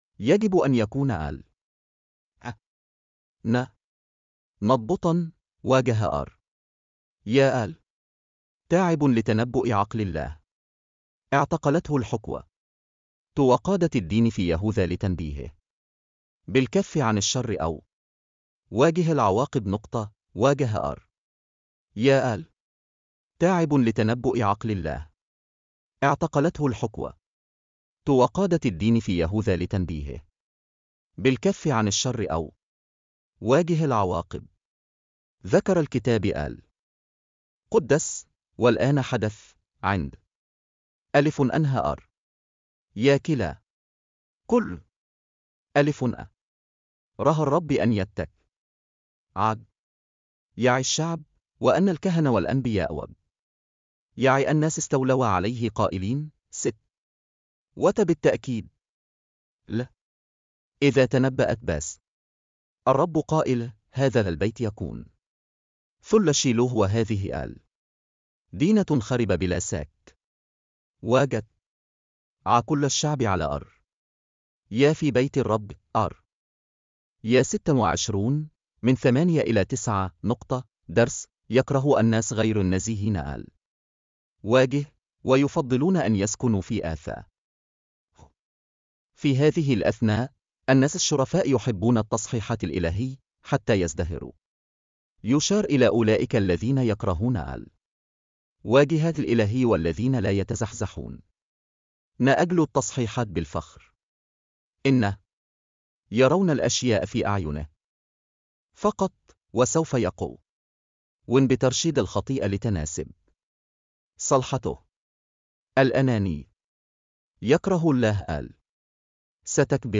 Posted On: Apr 26 - Devotion لله سر النجاح ساعدت الصلوات المستمرة الكنيسة الأولى على أن تكون ثابتة وناجحة.